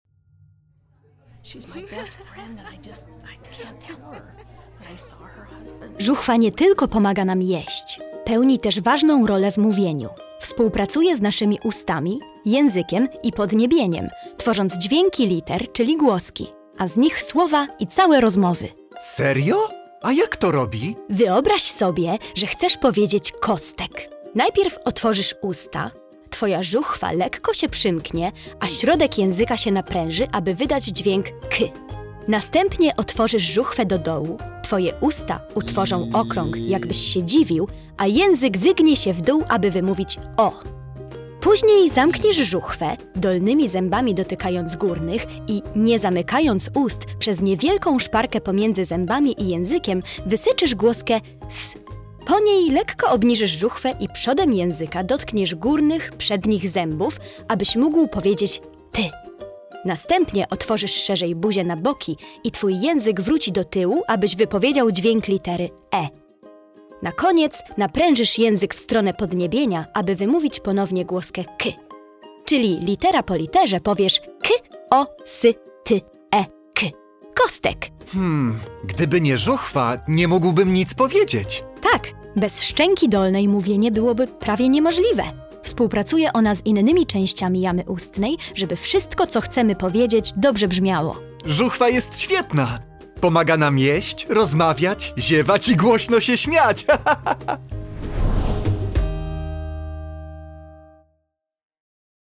Odkryj zabawne rozmowy, pełne ciekawostek i odgłosów Twojego organizmu.